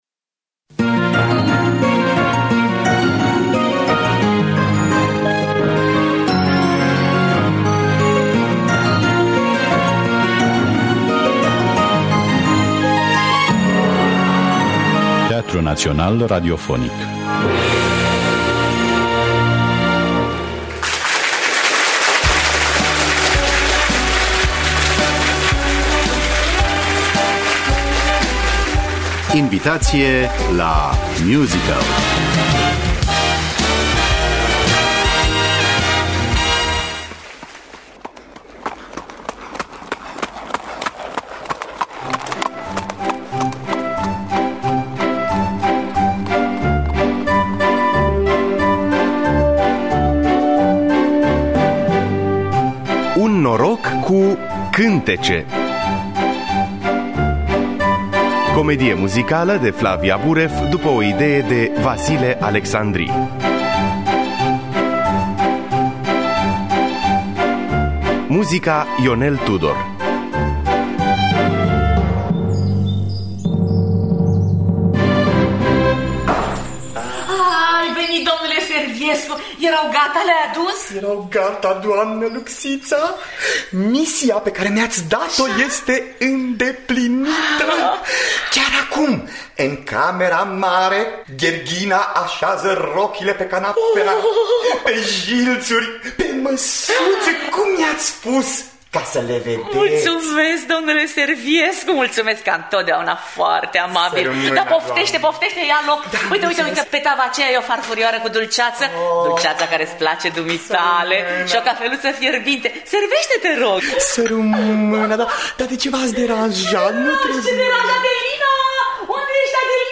Un noroc cu… cântece de Vasile Alecsandri – Teatru Radiofonic Online